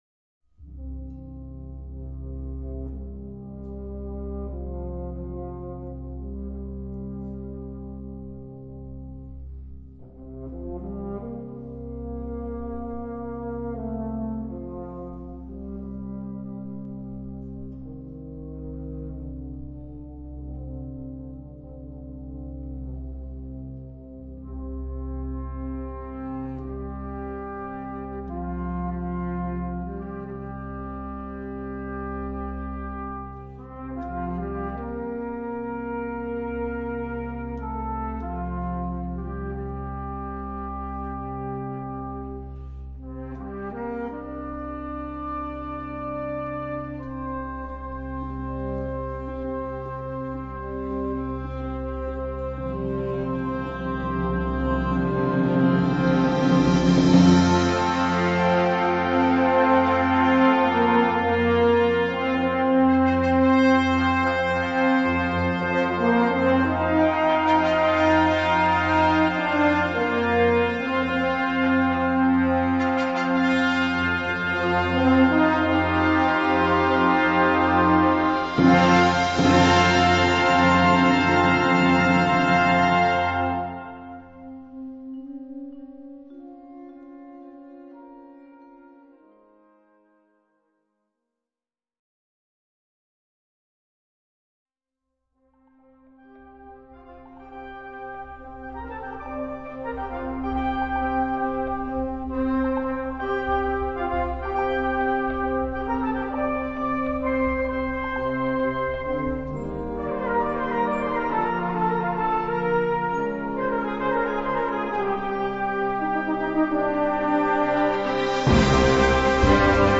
Subcategorie Concertmuziek
Bezetting Ha (harmonieorkest)